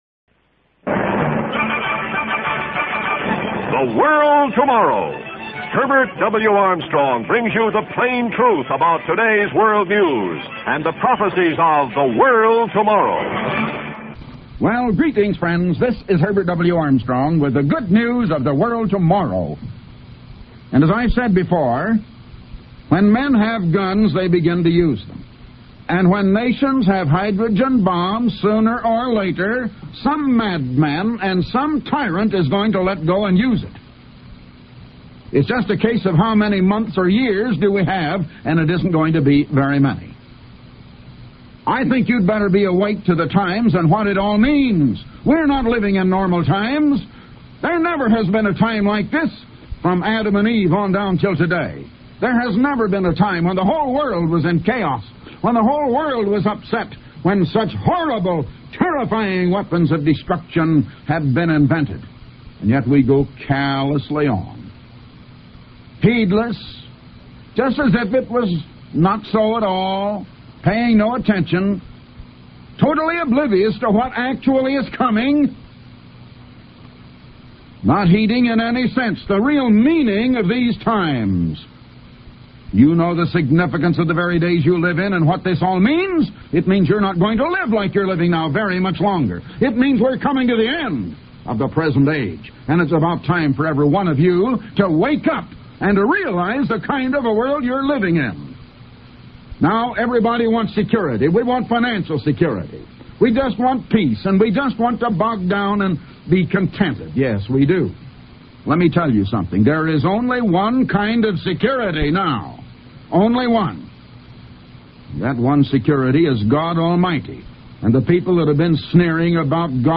Type: Radio Broadcast